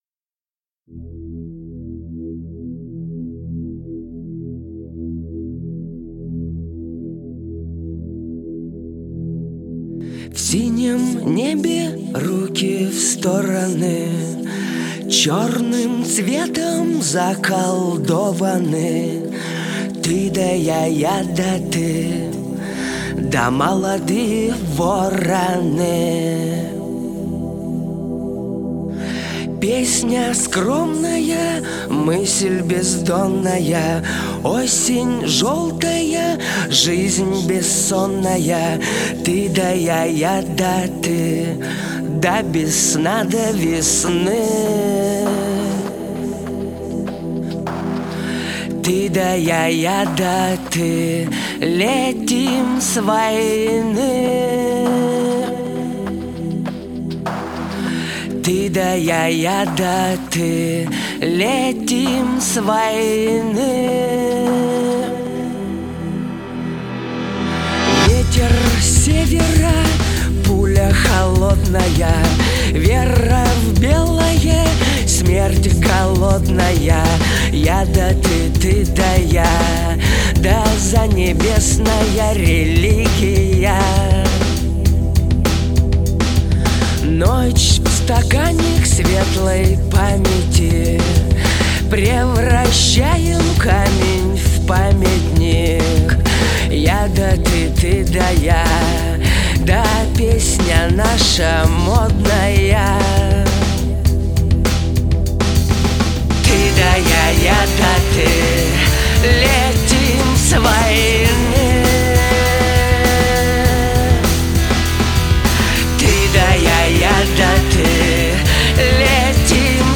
Категория: Военные песни